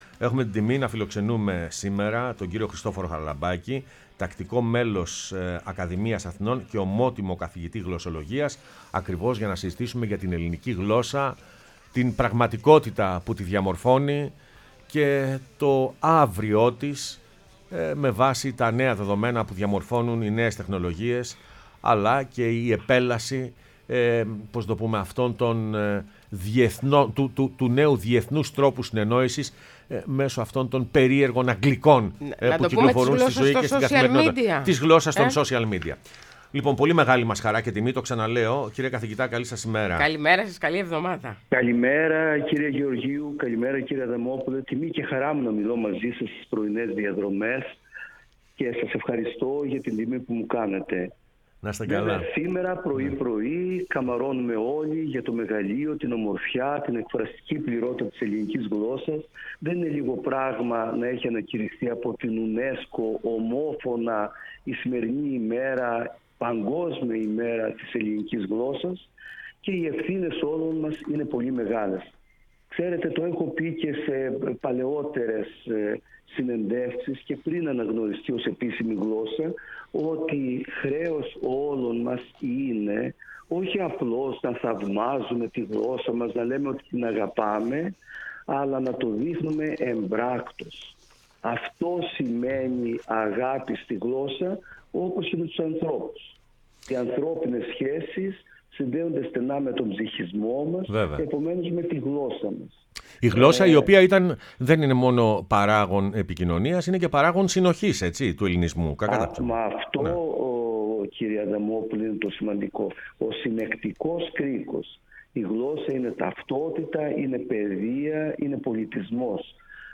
μίλησε στην εκπομπή «Πρωινές Διαδρομές»